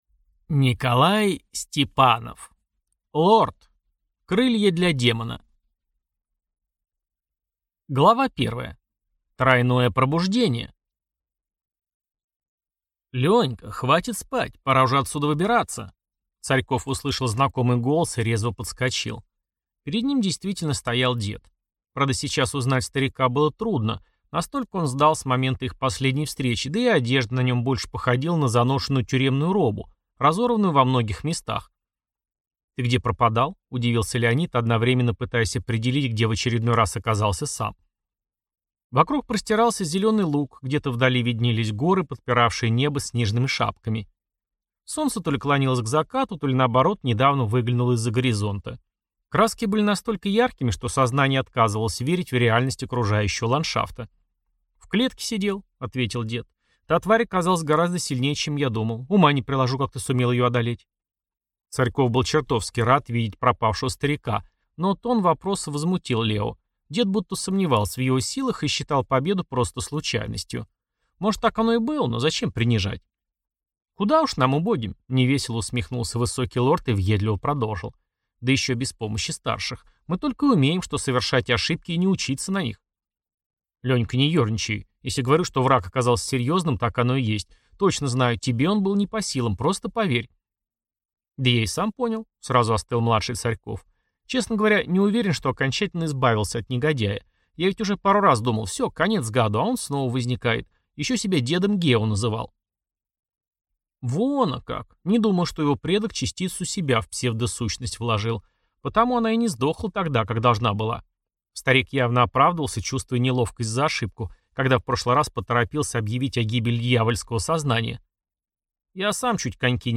Аудиокнига Лорд. Крылья для демона | Библиотека аудиокниг